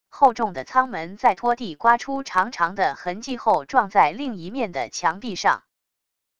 厚重的舱门在拖地刮出长长的痕迹后撞在另一面的墙壁上wav音频